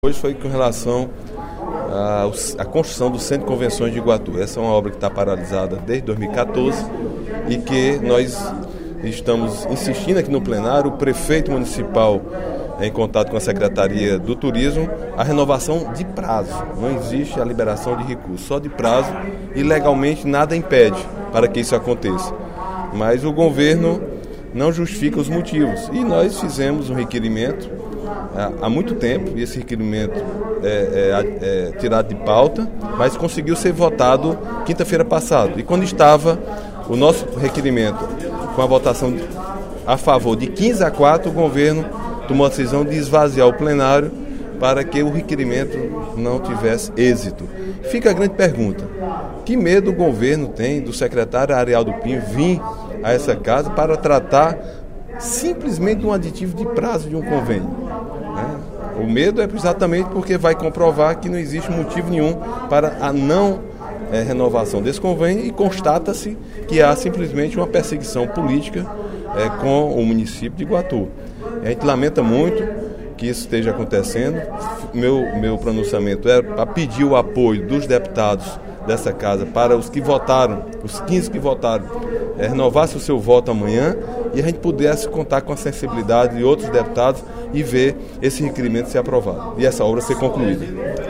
O deputado Agenor Neto (PMDB) voltou a cobrar, durante o primeiro expediente da sessão plenária desta quarta-feira (18/05), a renovação da assinatura do convênio para as obras do Centro de Eventos do município de Iguatu.